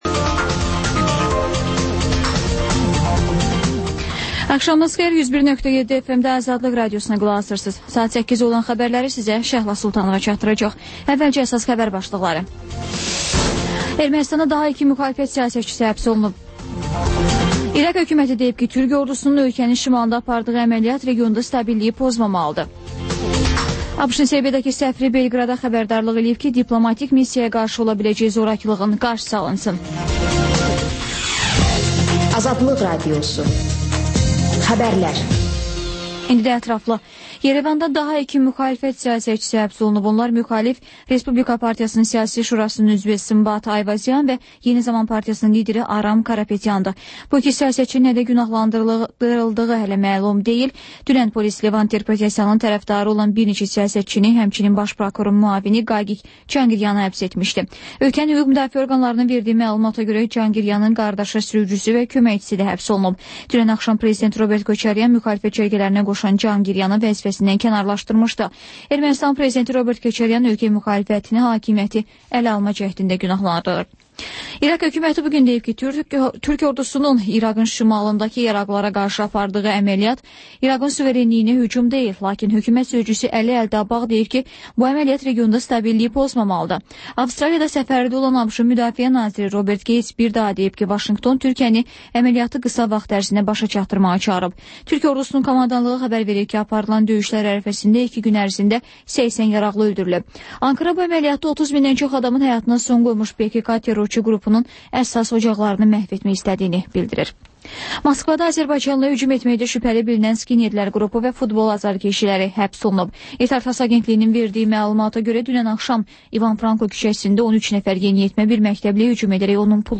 Xəbərlər, İZ: Mədəniyyət proqramı və TANINMIŞLAR rubrikası: Ölkənin tanınmış simalarıyla söhbət